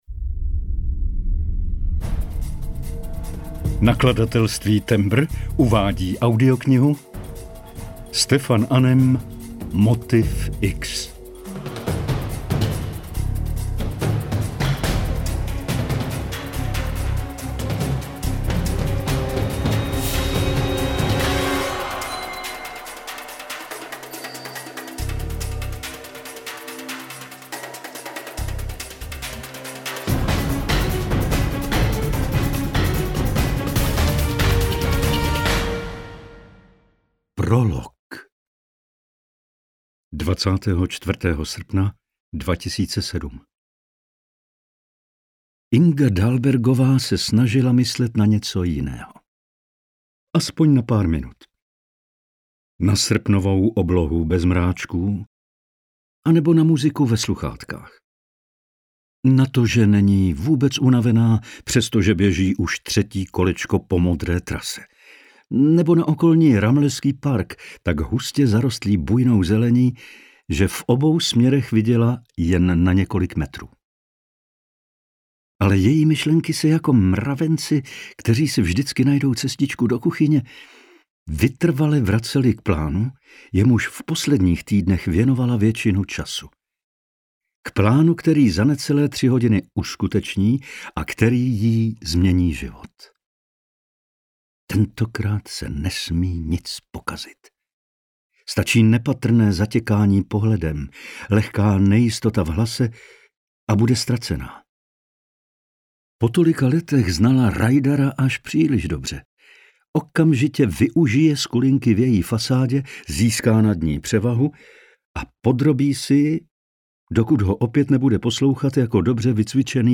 Motiv X – 2. vydání audiokniha
Ukázka z knihy